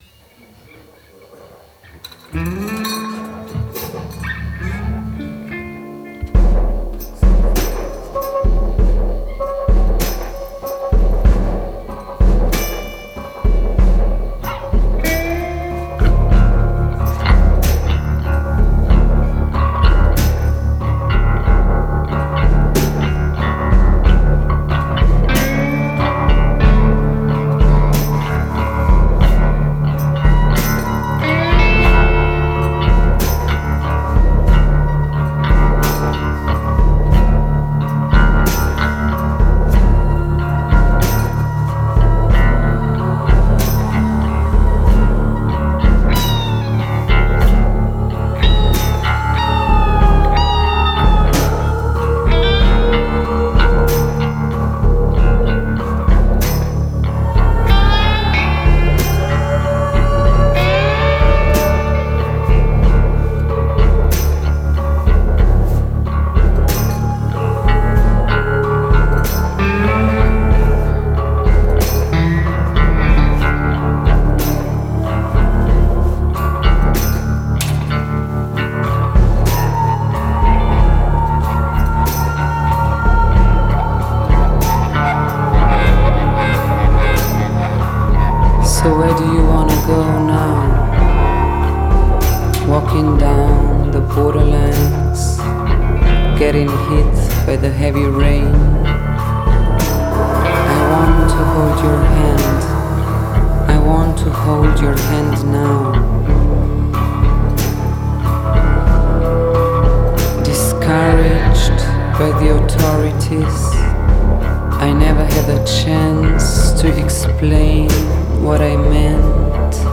Bassfl�tistin
Bassklarinettistin